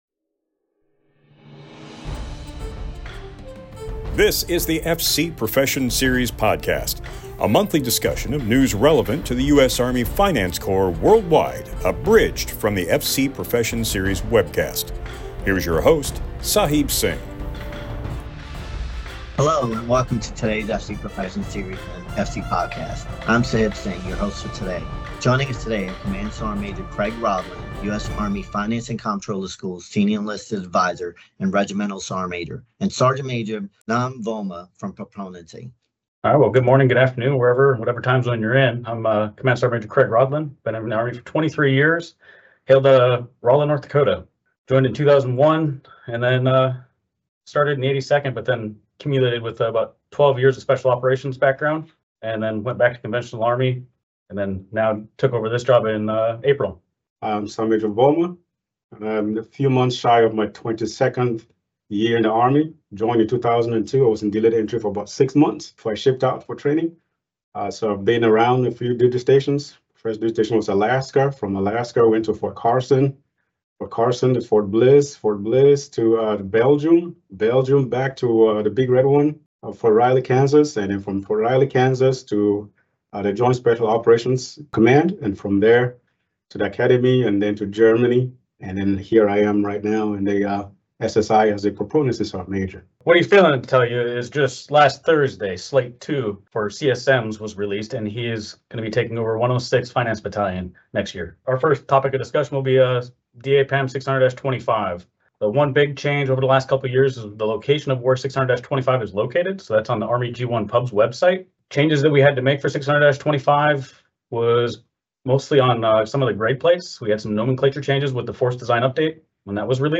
This episode is taken from the FC Profession Series full-length video which can be found on DVIDS.